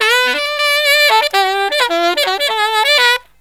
63SAXMD 10-L.wav